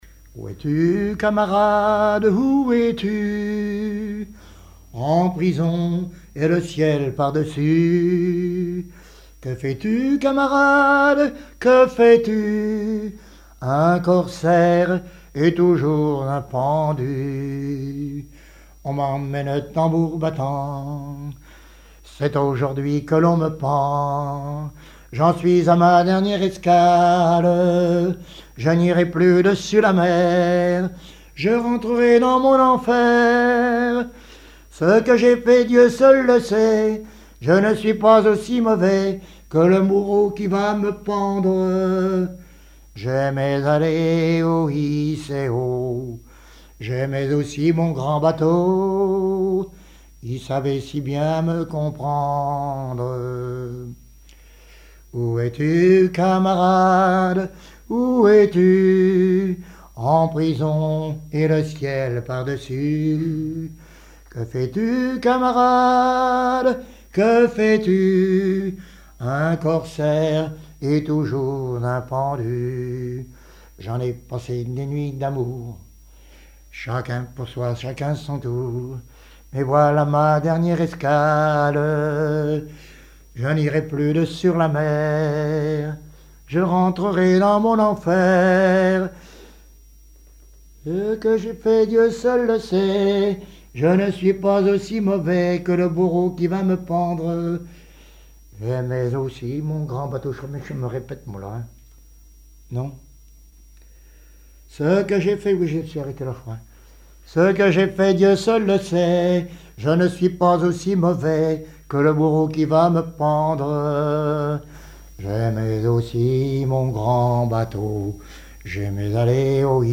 Genre strophique
Chansons populaires et témoignages
Pièce musicale inédite